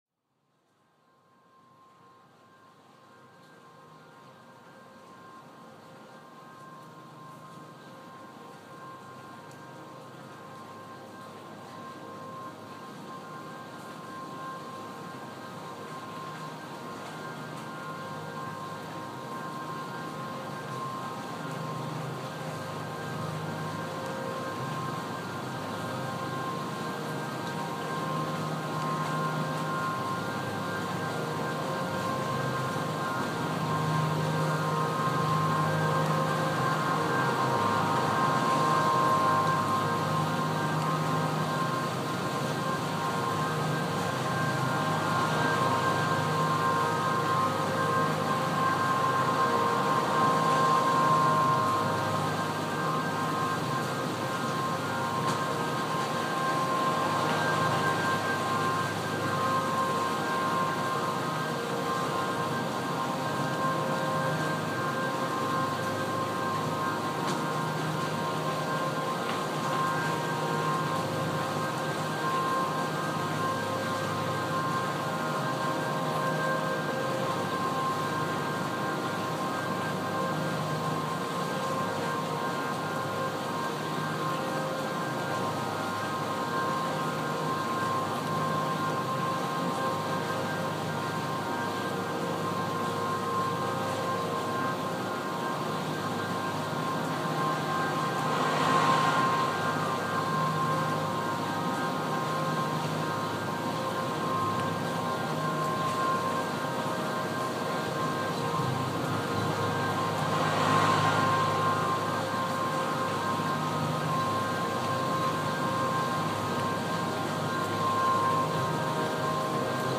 field recording from New York